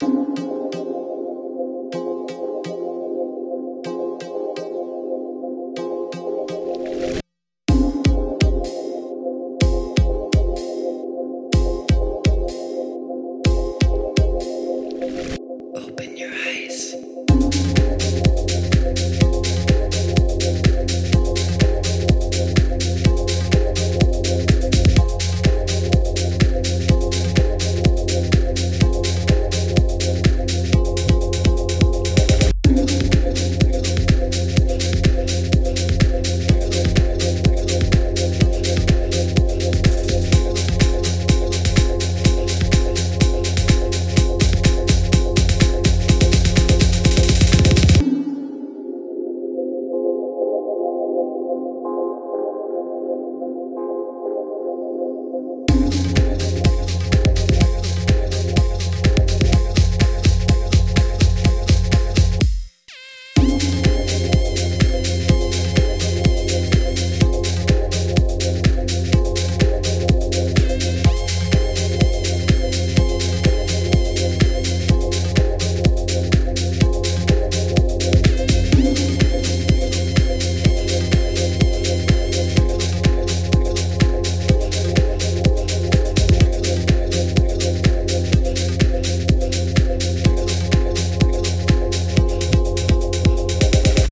BPM125
Audio QualityPerfect (High Quality)